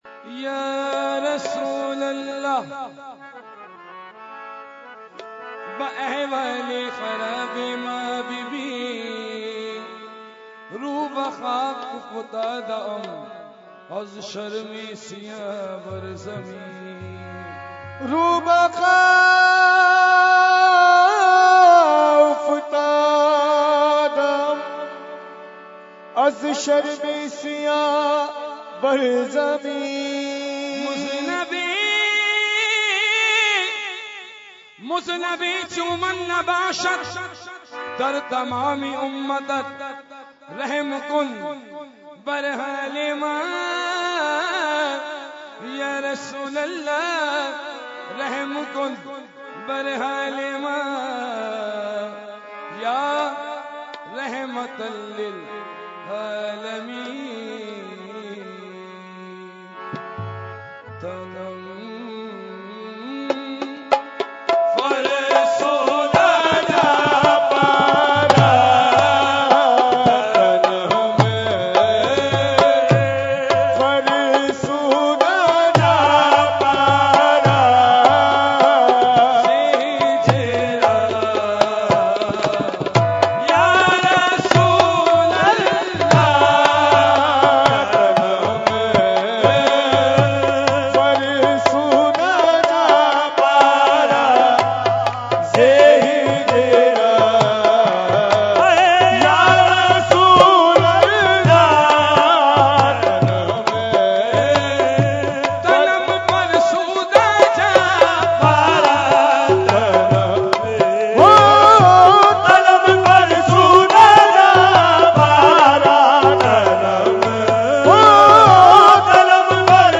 Category : Qawali | Language : UrduEvent : Urs Qutbe Rabbani 2019